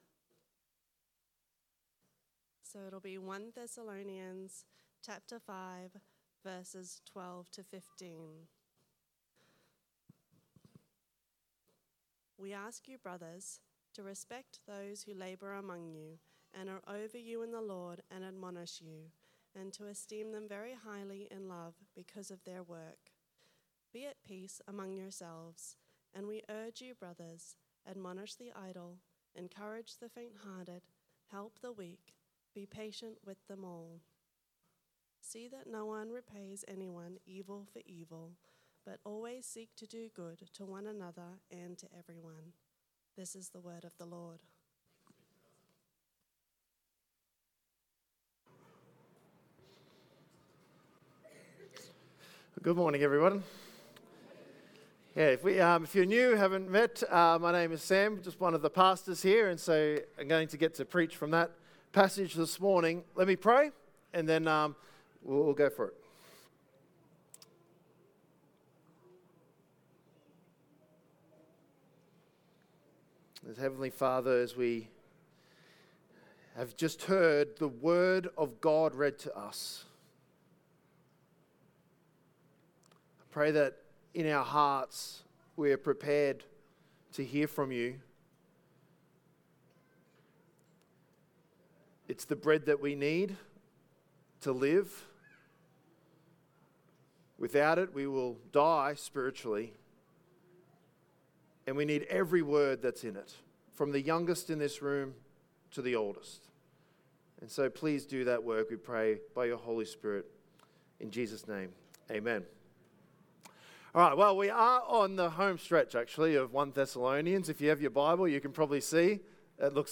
Sermons | Coomera Baptist Church